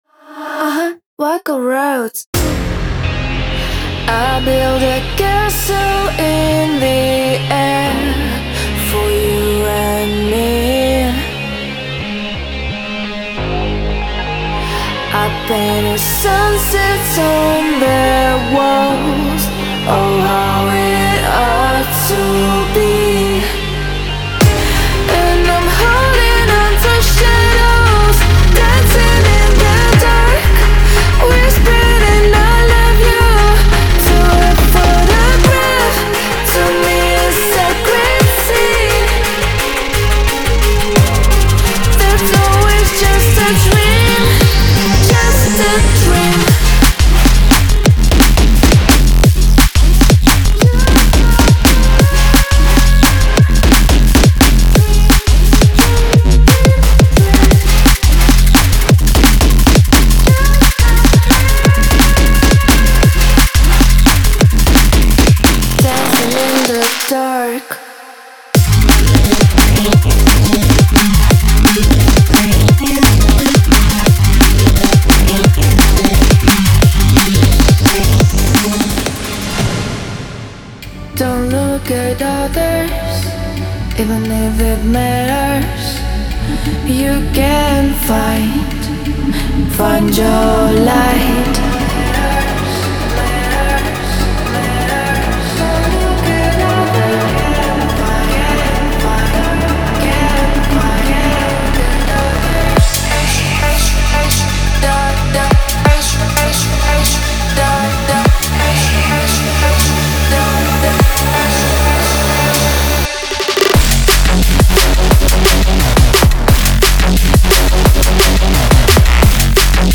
Genre:Neurofunk
本パックはジャンル特有のコントラストを表現しています。
最先端のベースとドラムループに、メランコリックで雰囲気豊かなボーカルが融合します。
さらに、荒々しいベースライン、鋭いドラム、シネマティックなシンセループを揃えたフルツールキットもサポートされています。
デモサウンドはコチラ↓